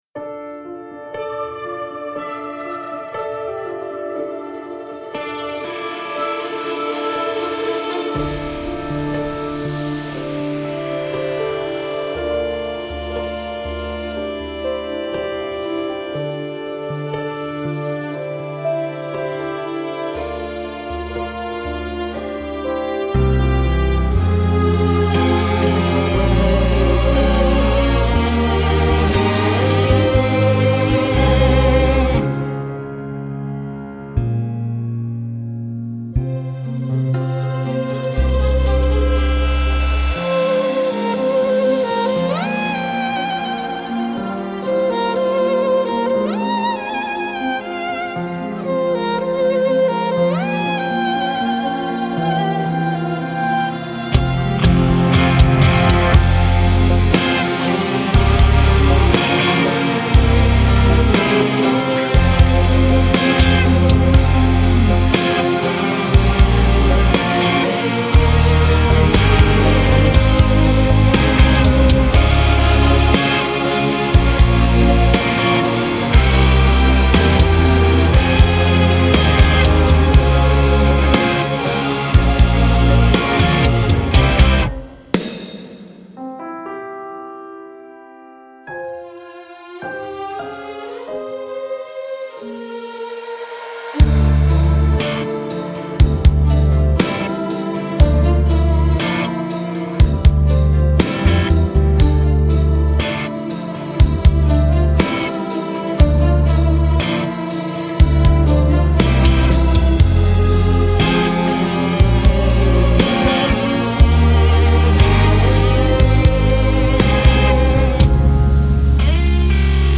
караоке
Скачать минус детской песни